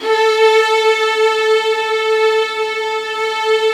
Index of /90_sSampleCDs/AKAI S-Series CD-ROM Sound Library VOL-7/ORCH STRINGS